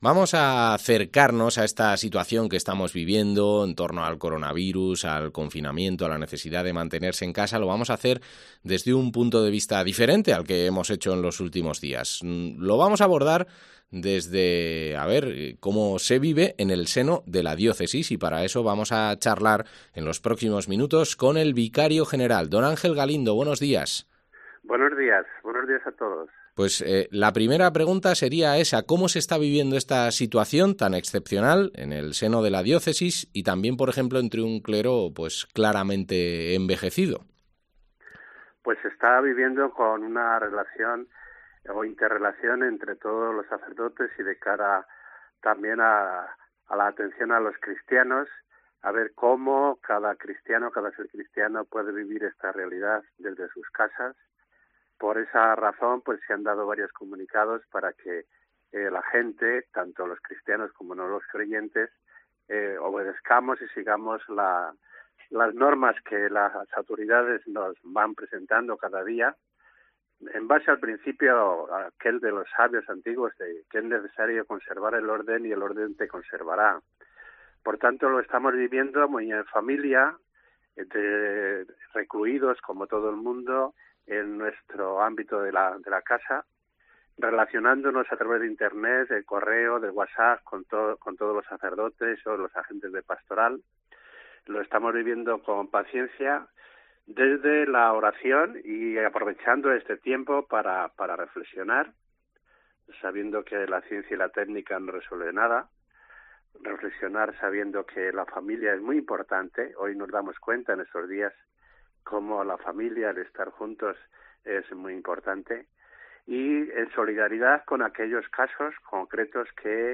Entrevista al vicario general